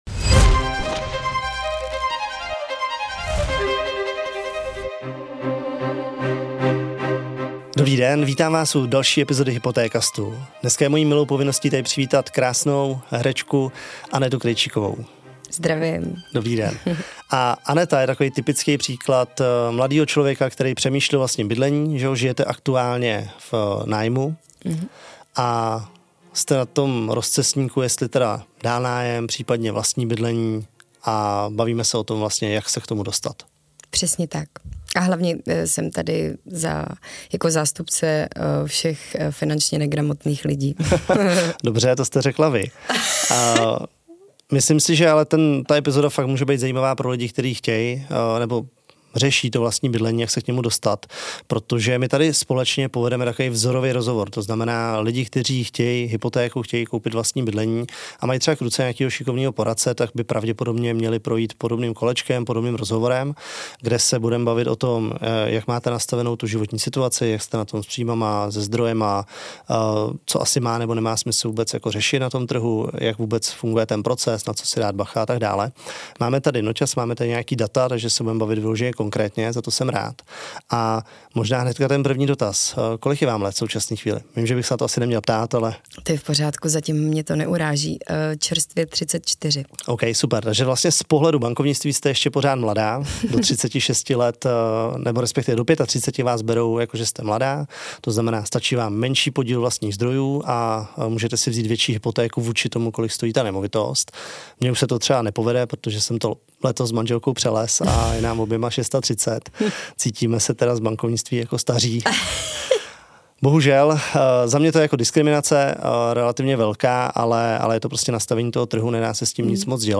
Jak se řeší hypotéka, když jste máma samoživitelka a hledáte nové bydlení? 🏠 V tomhle otevřeném rozhovoru s herečkou Anetou Krejčíkovou, kterou můžete znát ze seriálu Ulice se dozvíte, co všechno musí řešit, co ji zaskočilo a jak se vypořádat s jednou z největších životních in...